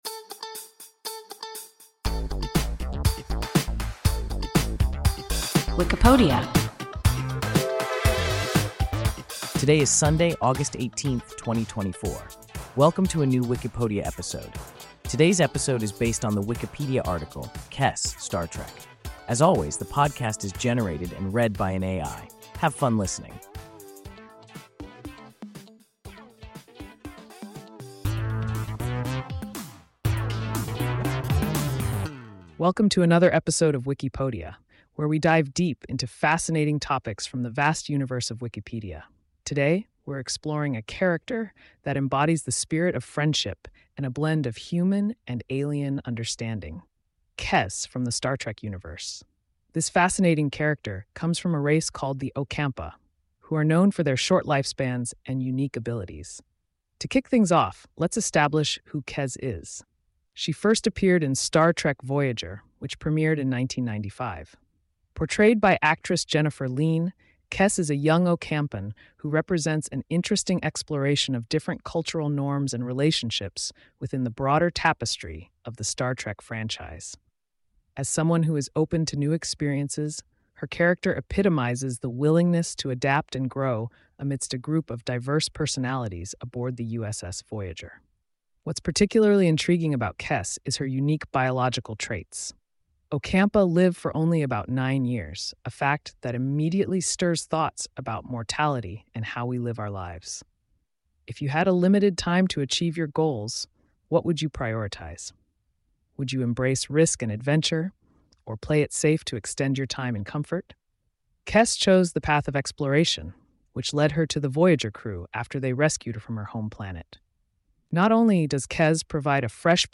Kes (Star Trek) – WIKIPODIA – ein KI Podcast